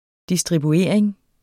Udtale [ disdʁibuˈeɐ̯ˀeŋ ]